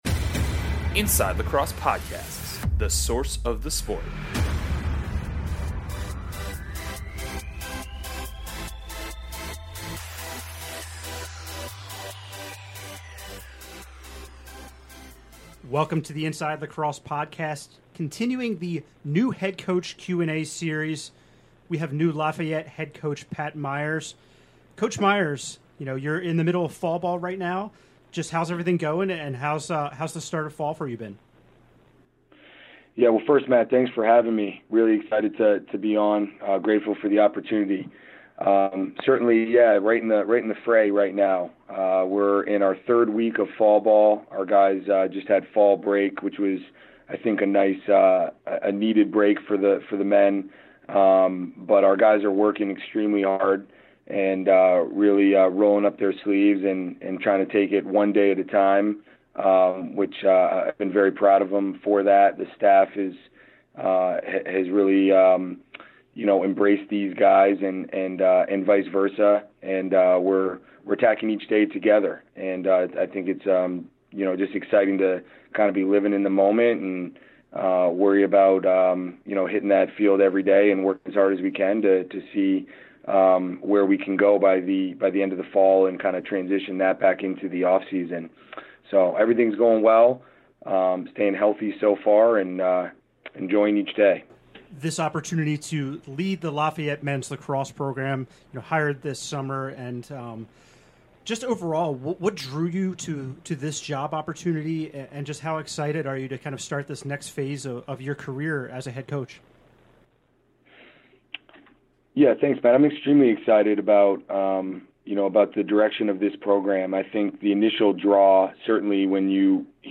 10/13 New Coach Interview